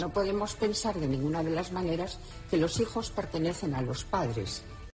Declaraciones de Isabel Celaá